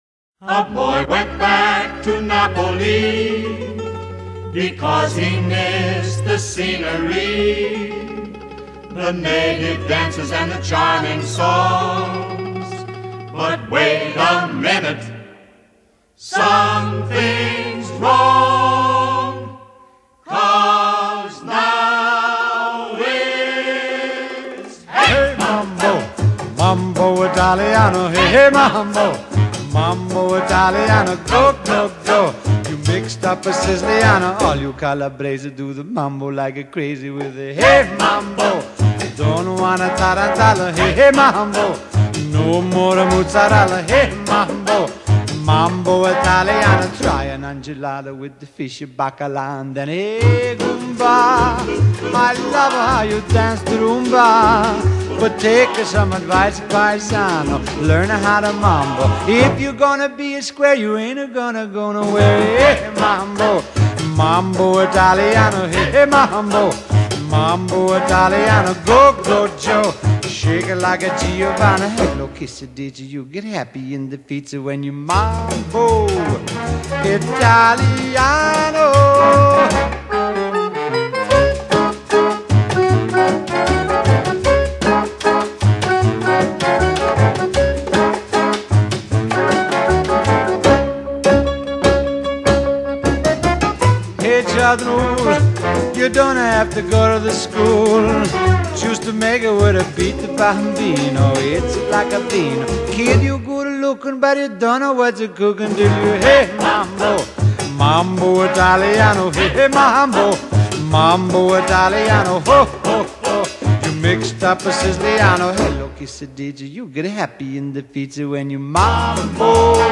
慵懒、抒情而迷人的嗓音